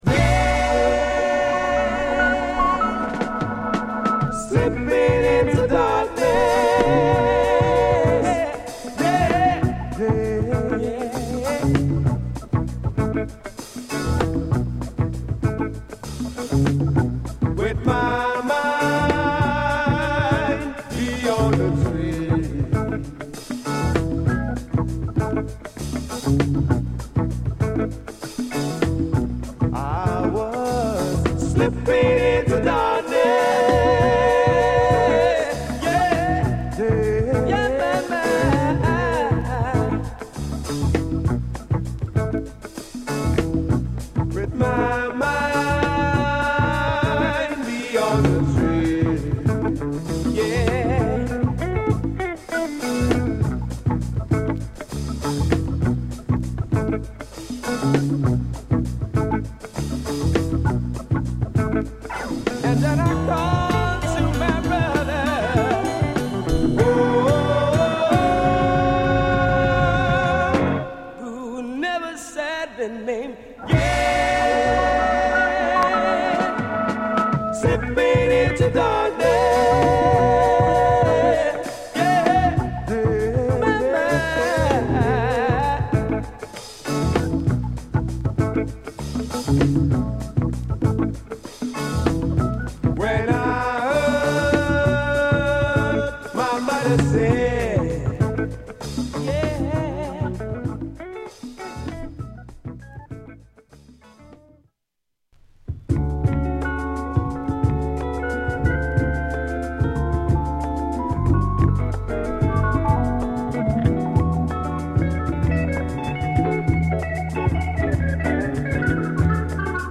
ディスコ調のものからメロウなものまで、ナイスソウルを満載の本作。
オススメのソウルアルバムです！